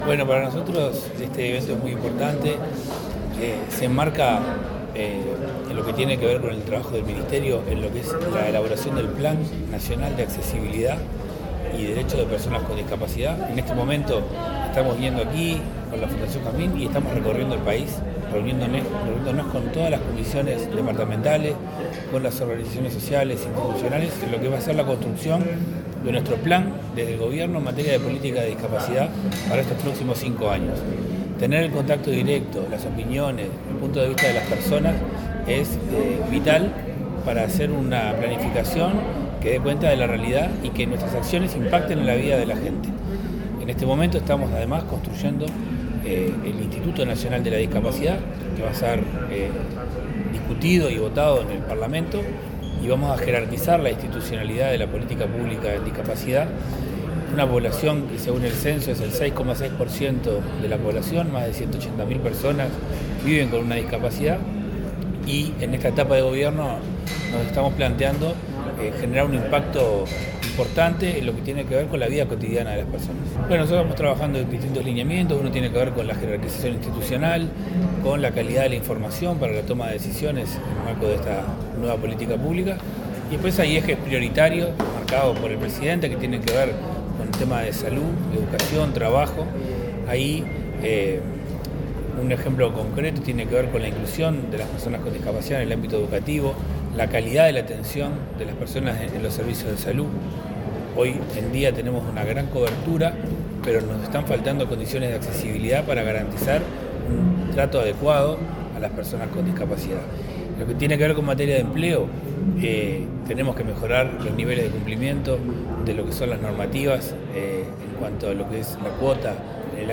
Declaraciones del director del área de Discapacidad del Ministerio de Desarrollo Social, Federico Lezama
Tras participar en la gala por el décimo aniversario de la fundación Jazmín, el director del área de Discapacidad del Ministerio de Desarrollo Social, Federico Lezama, habló de la creación del Plan Nacional de Discapacidad, que se ejecutará en el quinquenio con el aporte de los actores involucrados, agregó.